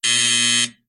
locked.wav